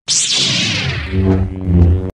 Download Free Star Wars Lightsaber Sound Effects
Download Star Wars Lightsaber sound effect for free.
Star Wars Lightsaber